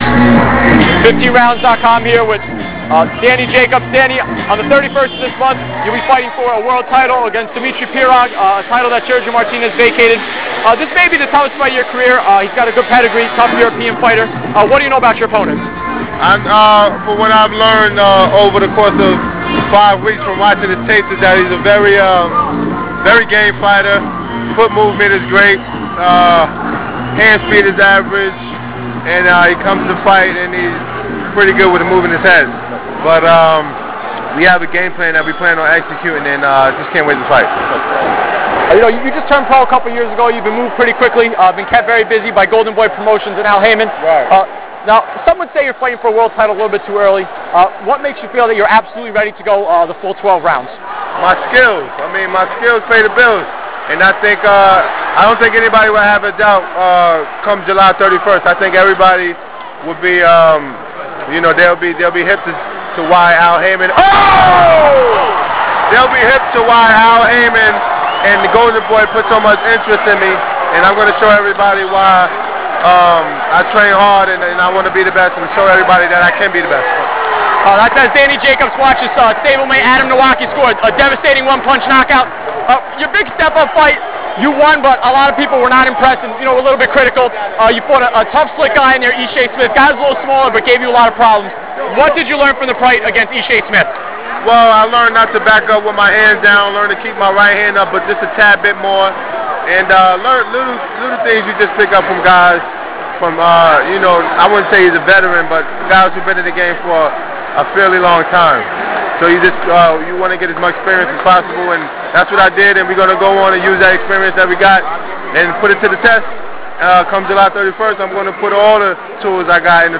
interview-with-danny-jacobs.wav